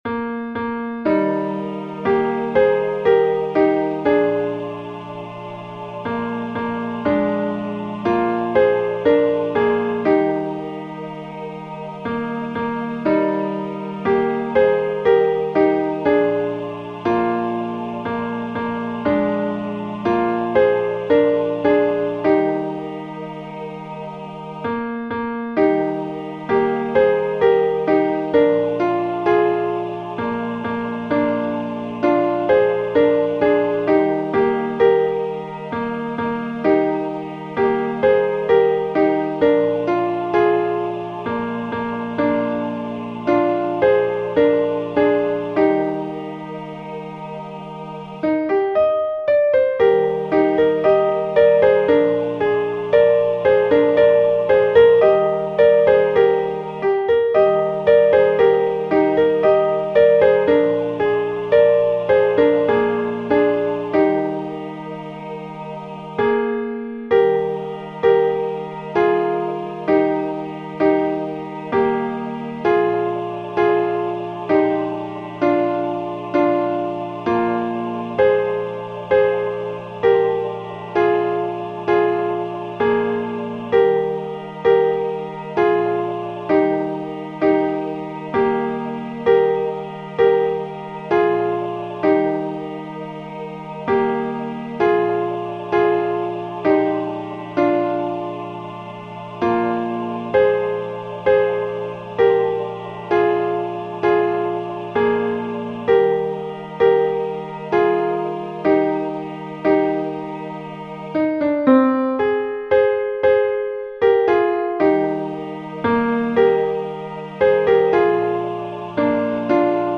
Demos zum Herunterladen